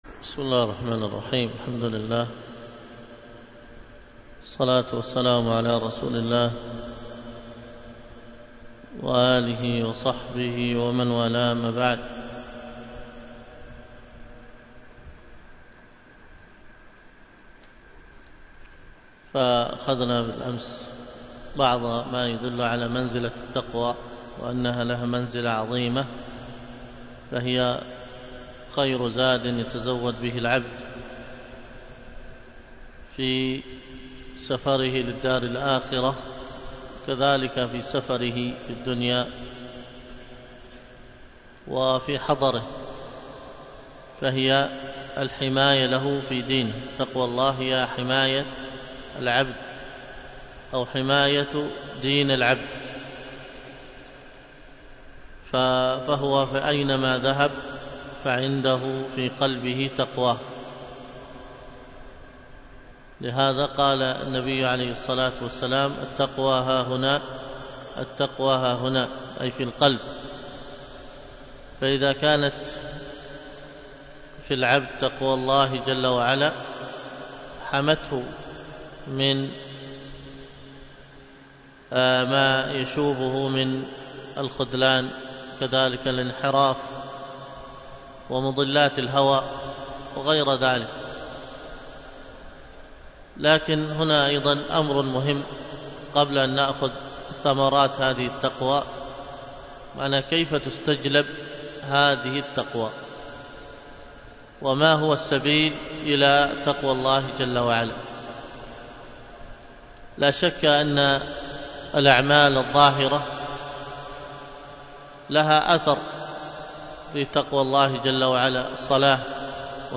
الدرس في كتاب الصيام 5